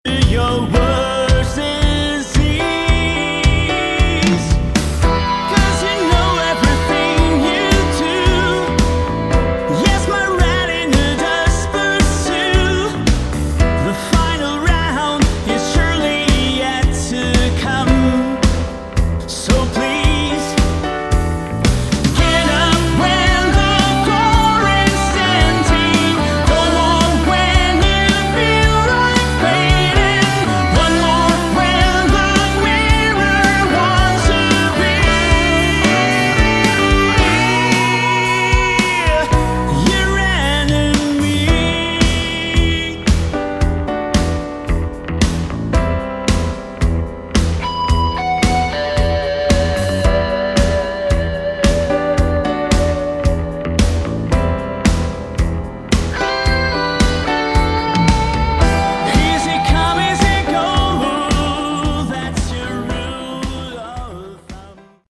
Category: AOR / Melodic Rock
vocals
guitars
keyboards
bass
drums